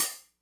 hihat02.wav